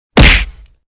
Punch Sound Effect - Gaming SFX.mp3